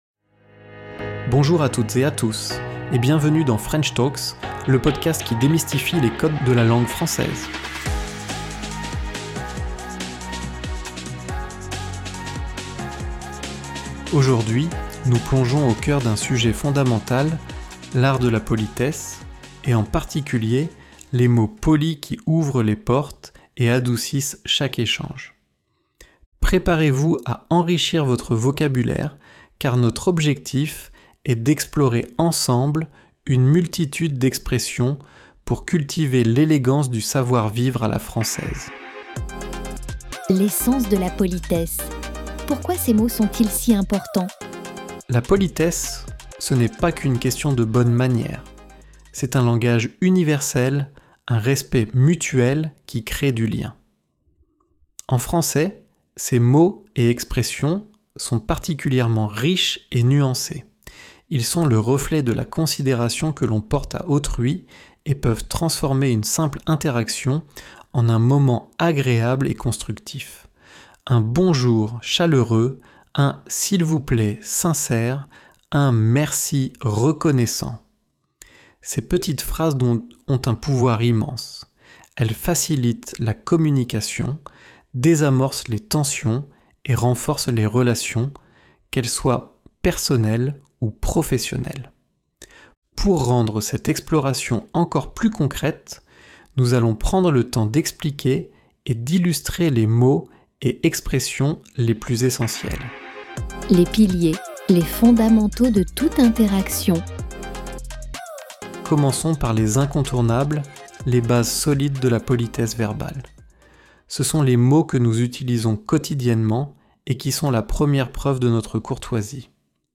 Apprenez le français avec un dialogue pratique + PDF.